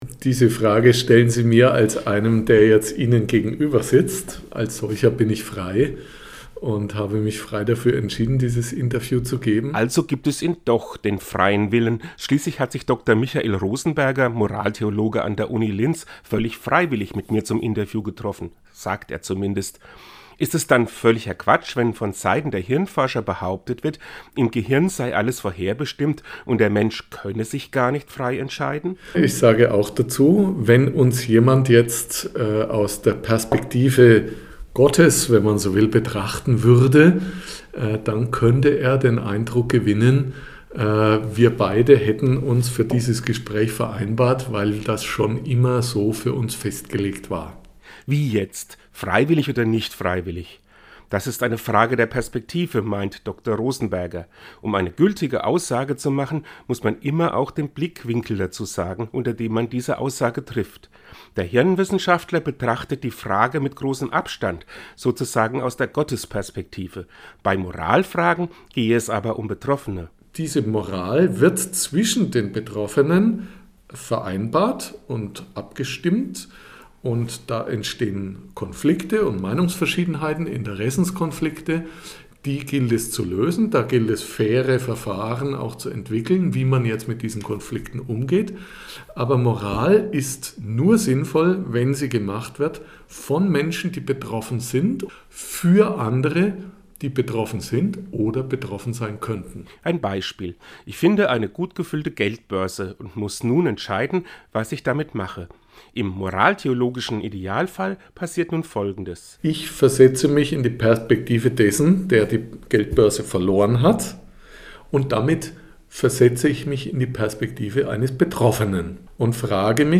Seine Antwort hören sie im Radiobeitrag unten als Download!